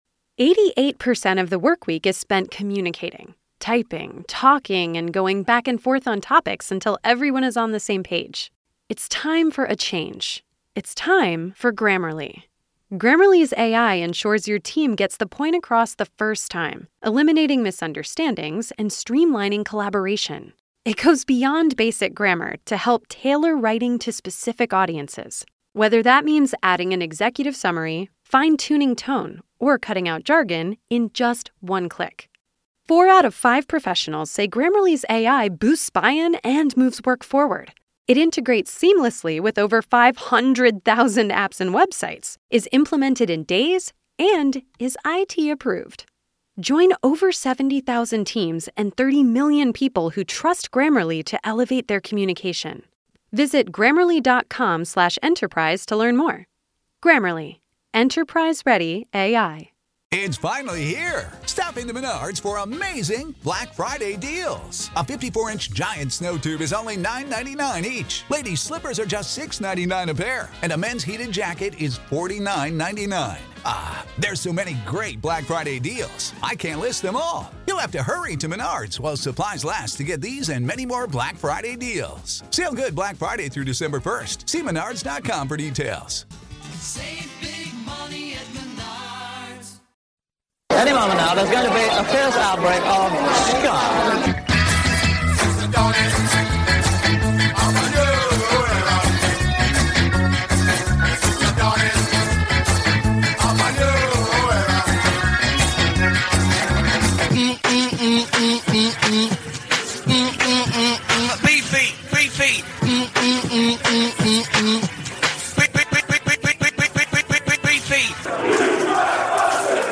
**THE WORLD'S #1 SKA SHOW - SKA NATION RADIO - FOR YOUR LISTENING PLEASURE **